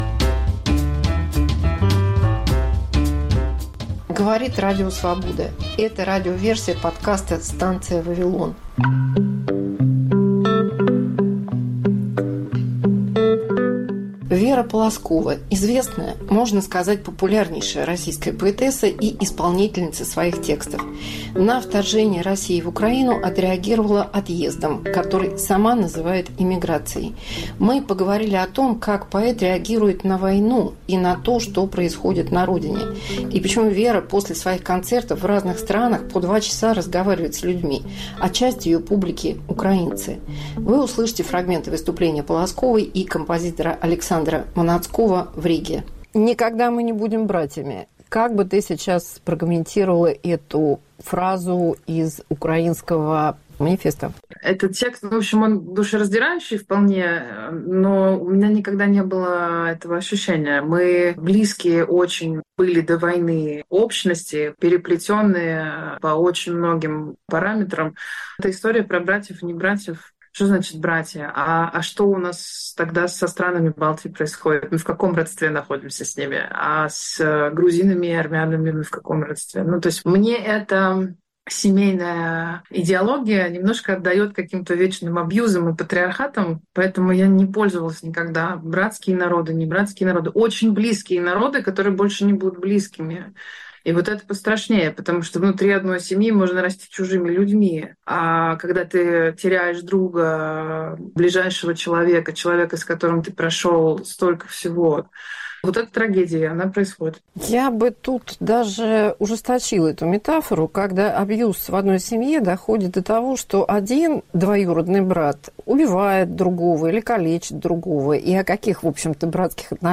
Интервью с Верой Полозковой